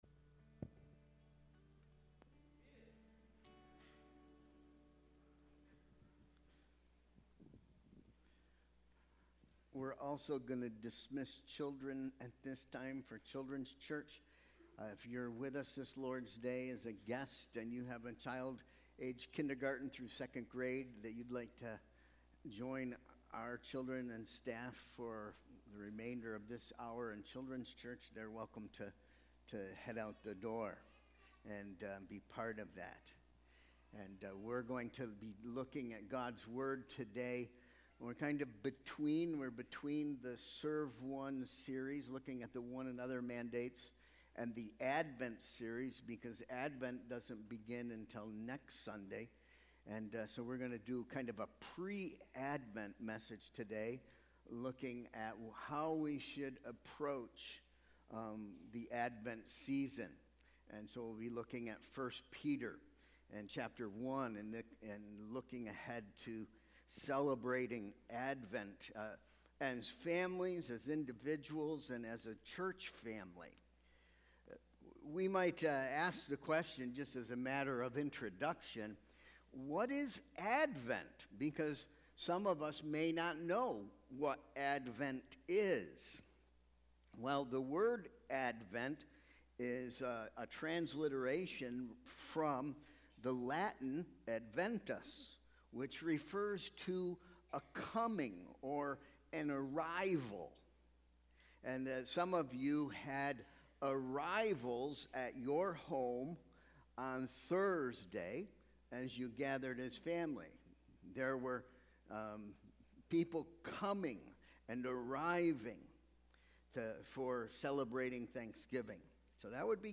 Sermon Proposition: You will personally benefit most from the Advent Season by aligning your approach to it with those who have come before us.